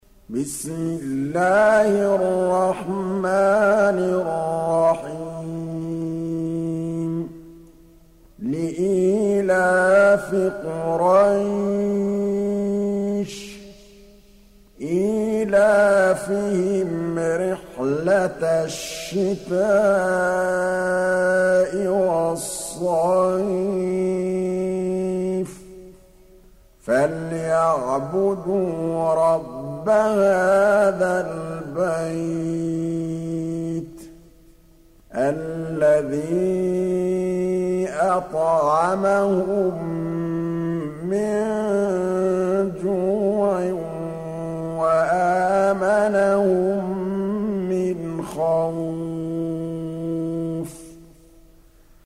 106. Surah Quraish سورة قريش Audio Quran Tarteel Recitation
Surah Sequence تتابع السورة Download Surah حمّل السورة Reciting Murattalah Audio for 106. Surah Quraish سورة قريش N.B *Surah Includes Al-Basmalah Reciters Sequents تتابع التلاوات Reciters Repeats تكرار التلاوات